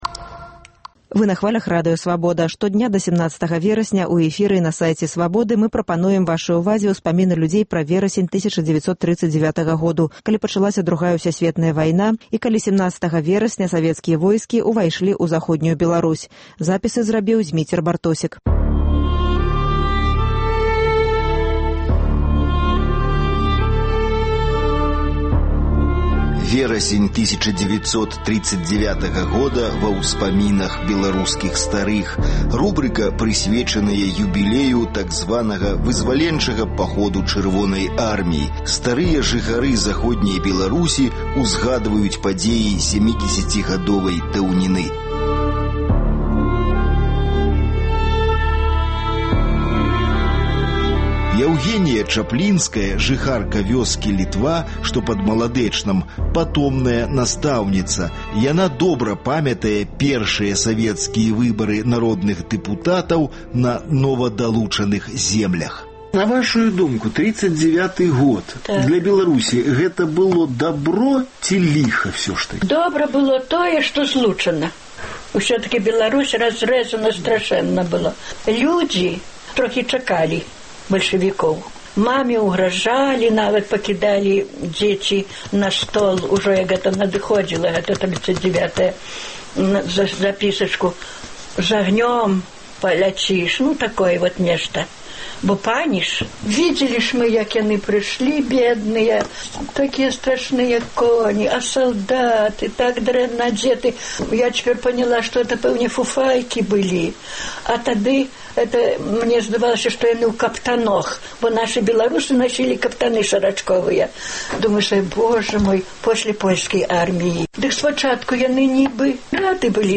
Штодня да 17 верасьня ў эфіры і на сайце "Свабоды" мы прапануем вашай увазе успаміны людзей пра верасень 1939 году, калі пачалася Другая ўсясьветная вайна і калі 17 верасьня савецкія войскі ўвайшлі ў Заходнюю Беларусь.